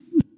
Click Back (1).wav